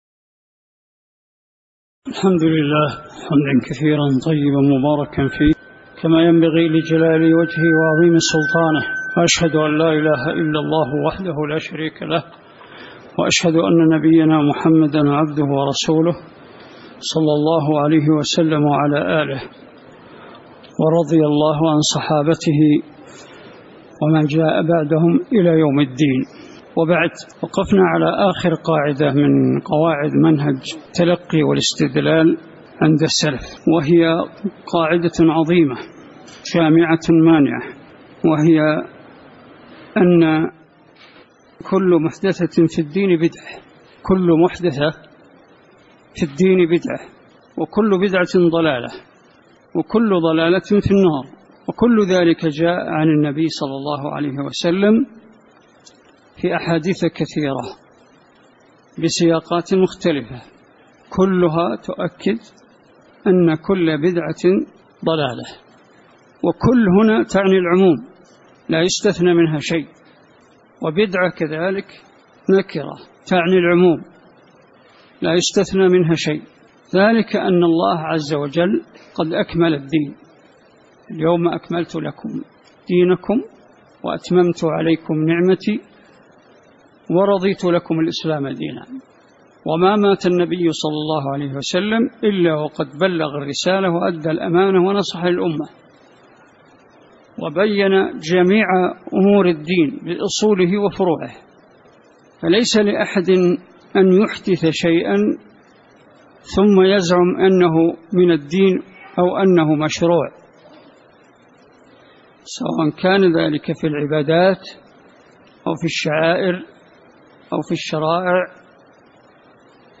تاريخ النشر ١٧ ذو القعدة ١٤٣٨ هـ المكان: المسجد النبوي الشيخ